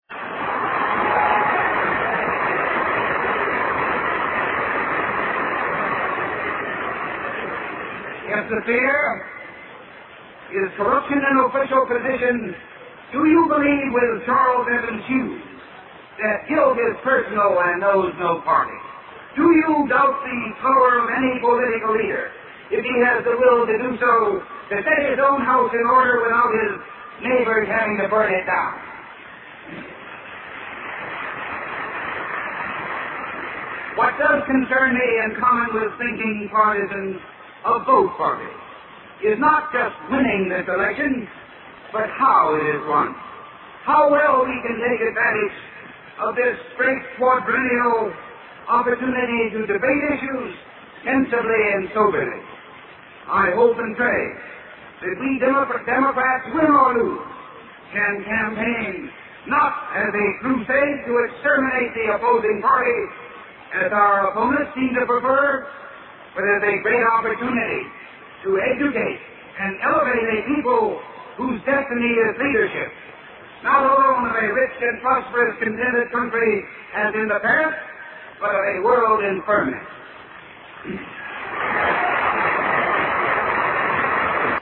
经典名人英语演讲(中英对照):Presidential Nomination Acceptance Speech 7